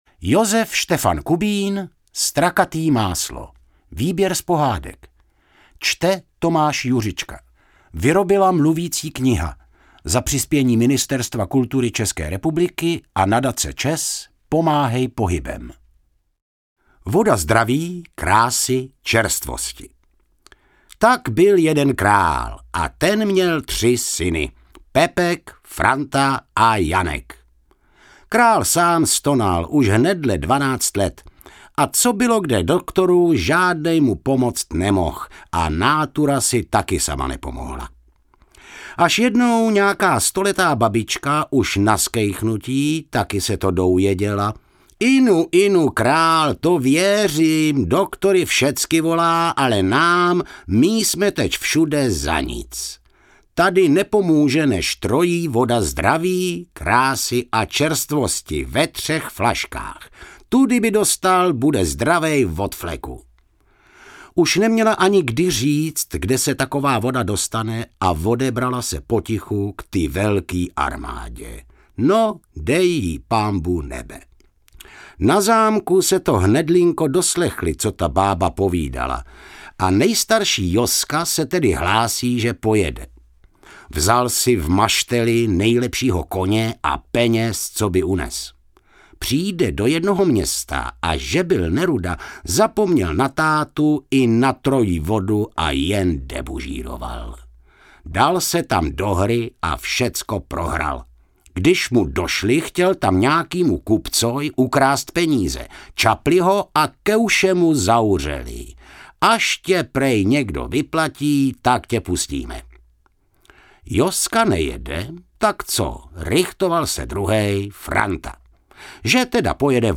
Lidové pohádky, vyprávěnky a "poudačky" z Kladska a Podkrkonoší vyprávěné jadrnou řečí našich předků a sebrané významným českým folkloristou a spisovatelem. Humorné, laskavé i laškovné čtení.